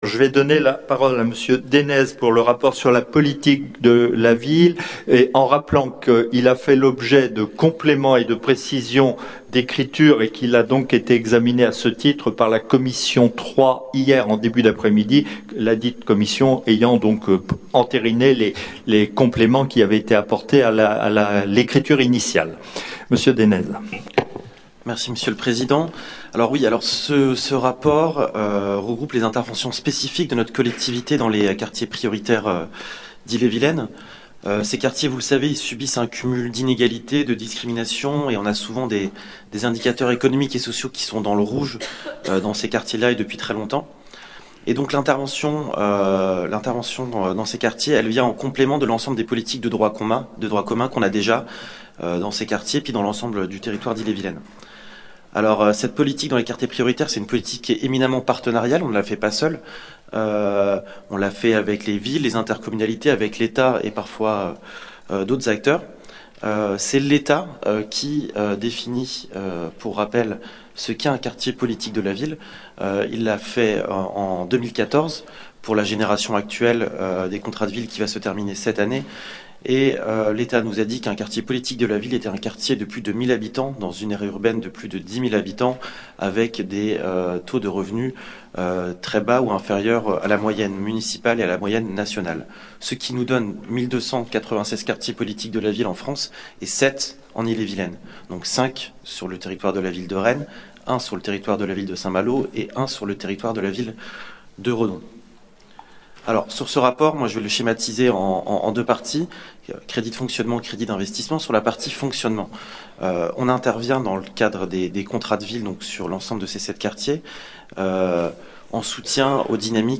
• Assemblée départementale du 08/02/23